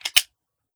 38 SPL Revolver - Open Barrel 001.wav